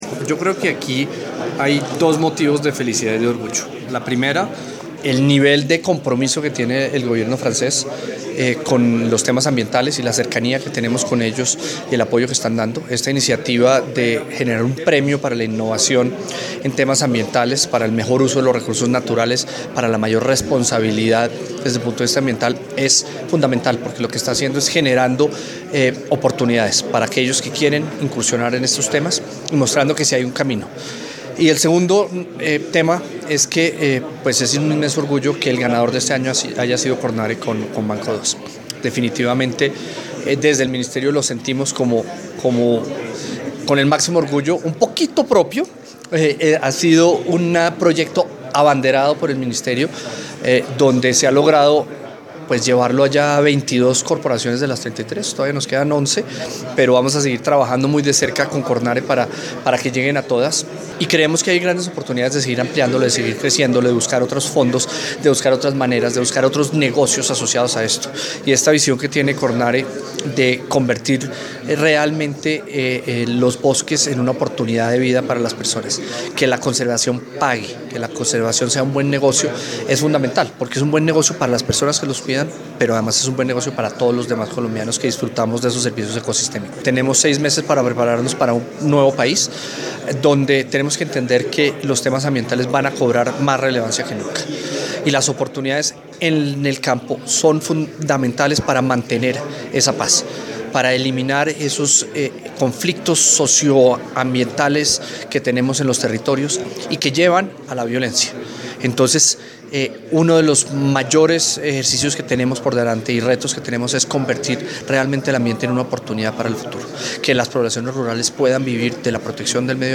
Declaraciones del Viceministro de Ambiente y Desarrollo Sostenible, Pablo Vieira Samper